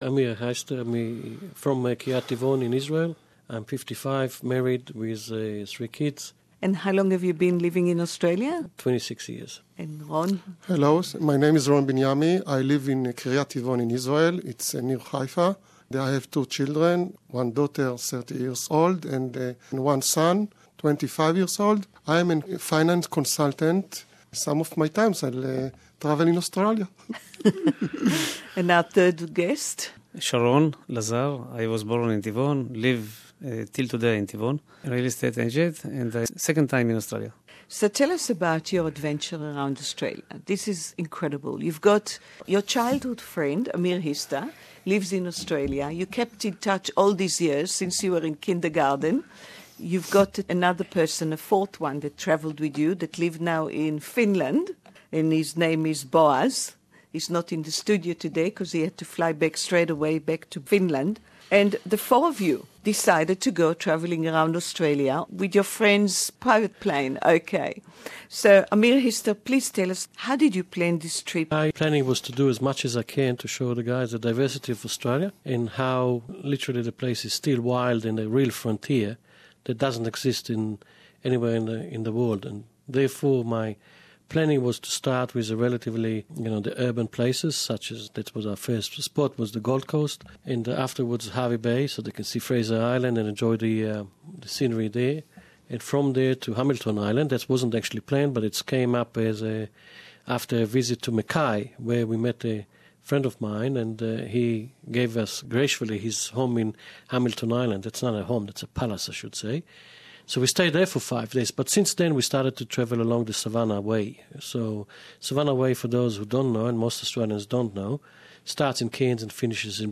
Around Australia for 30 Days on a Private Plane...Amazing story of 4 childhood friends from Israel (English Interview)